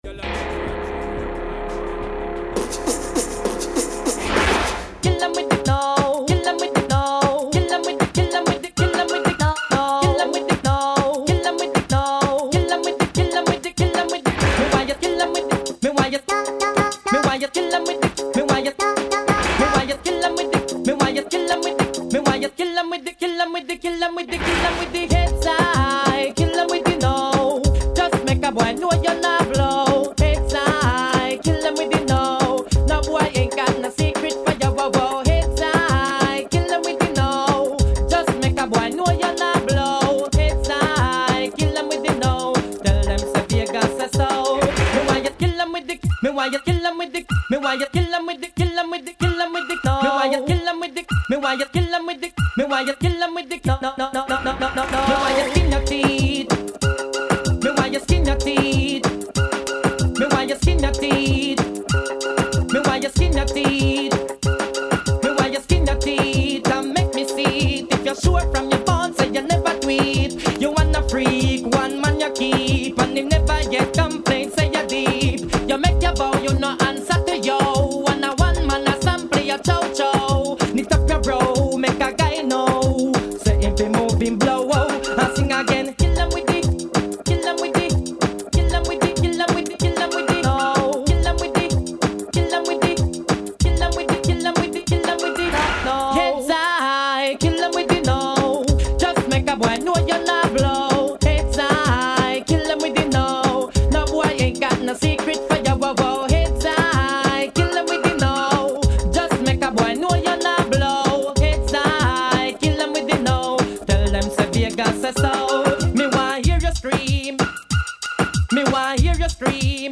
dance/electronic
THIS IS A REMIX OF A CLASSIC DANCE HALL TRACK.